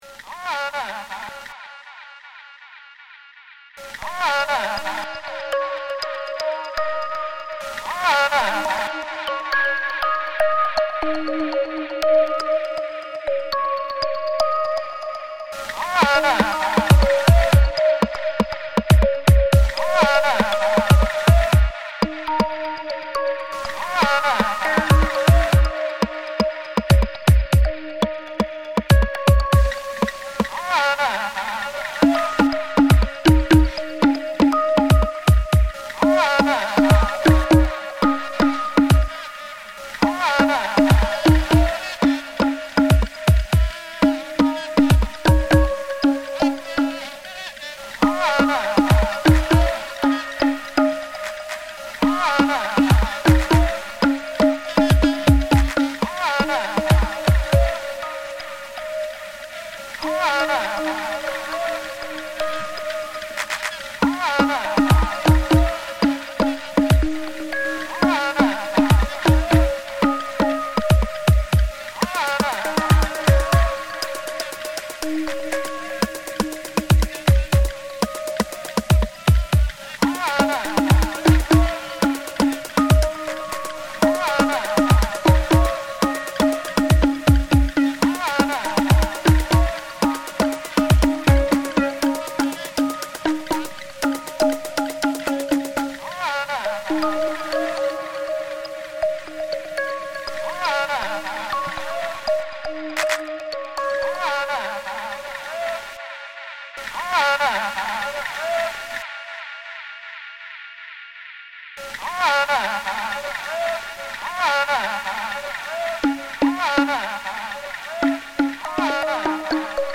For this creative project, my starting point was an archival recording of a Yoruba chant from Nigeria, recorded around 1911.
In my creative process, I deliberately chose to work with electronic instruments.